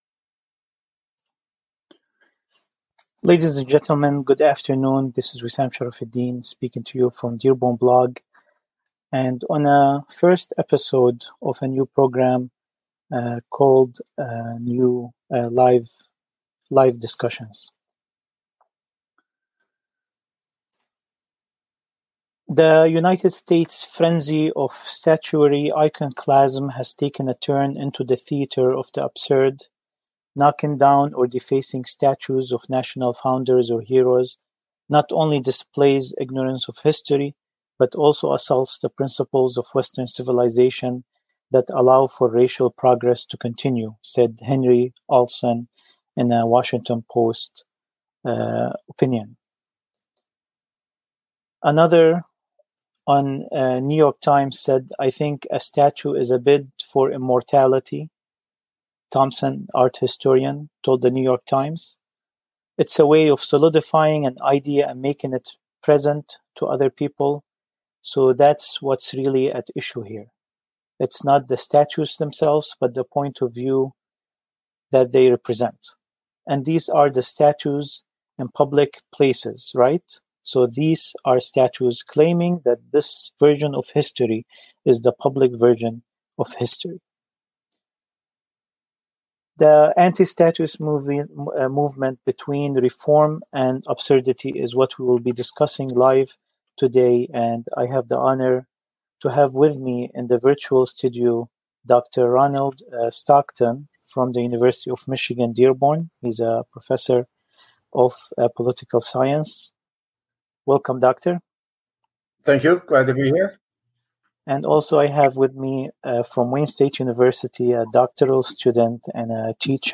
Eps. 1 Dearborn Blog Live Discussions – Anti-Statue Movement, Between Reform & Absurdity [Video/Podcast]